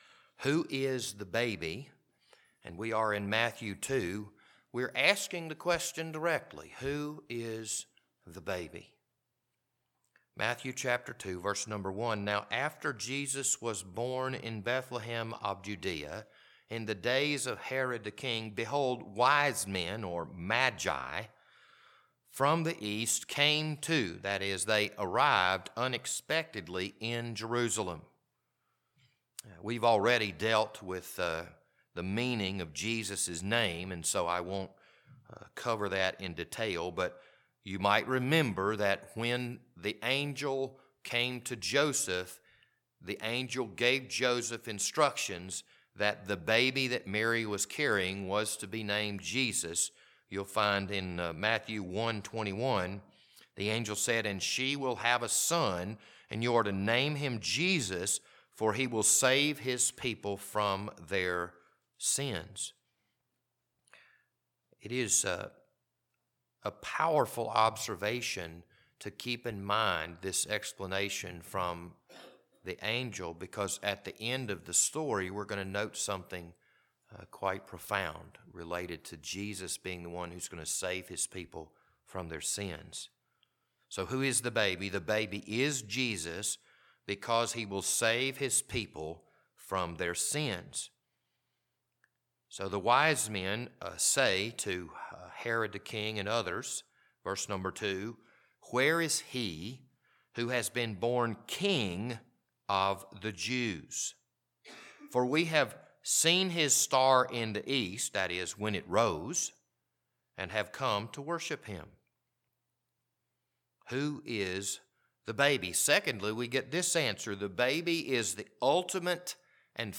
This Sunday evening sermon was recorded on October 22nd, 2023.